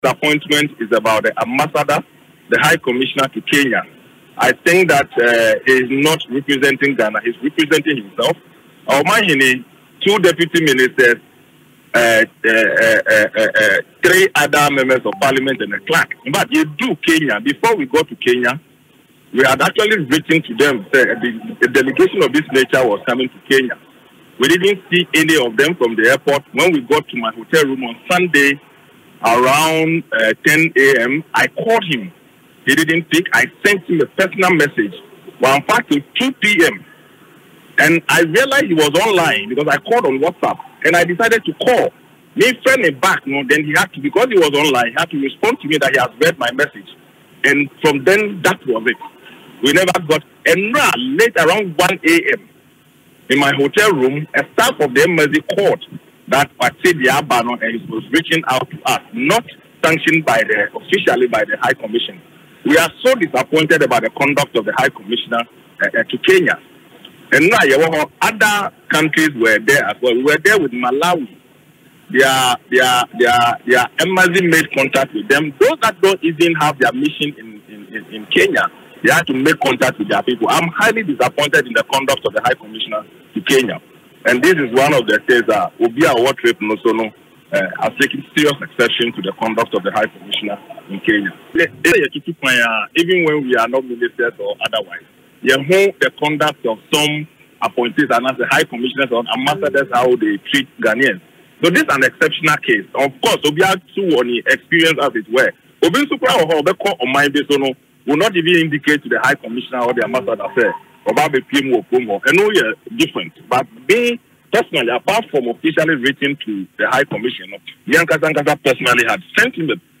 In an interview on Asempa FM Ekosii Sen programme Wednesday, the disgruntled MP indicated that, he informed the High Commission of the trip to Kenya before their arrival on Sunday.
Sylvester-Tetteh-scolds-High-Commissioner-.mp3